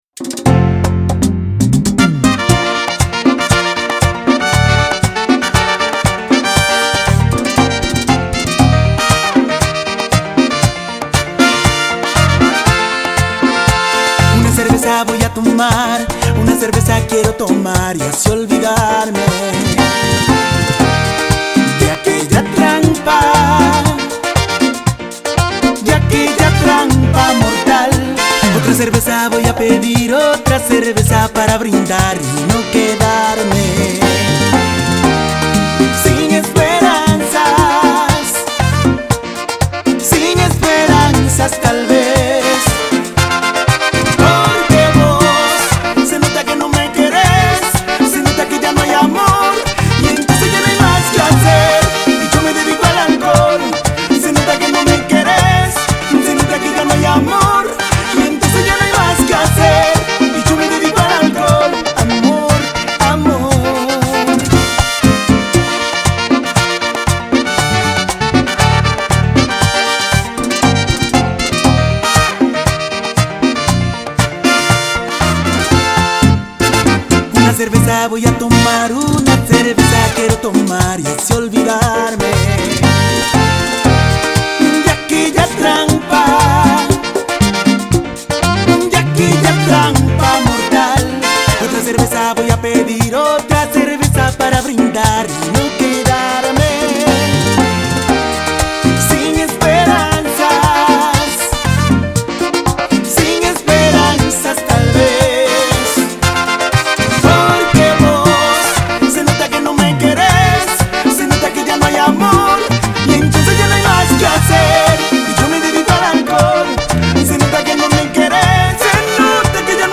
La versión merenguera
es un tema fresco, bailable, de gran recordación